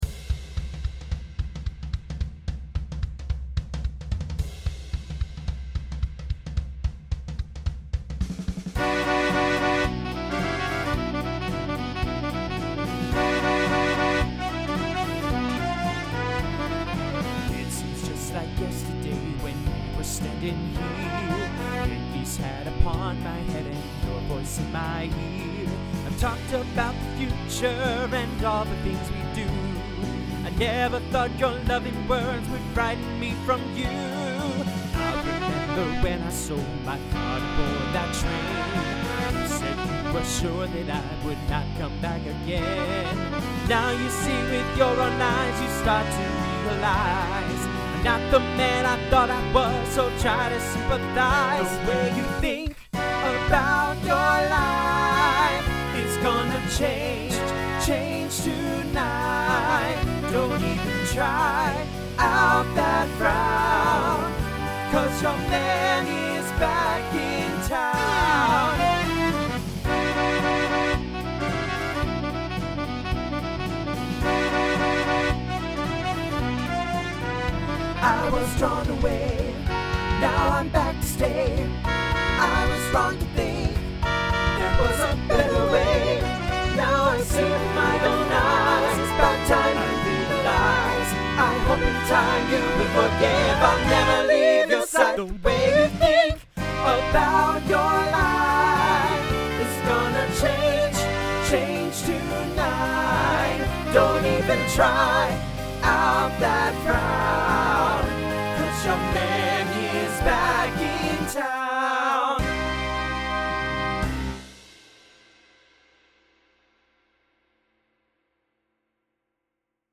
Some SATB at the end
Rock , Swing/Jazz
Transition Voicing TTB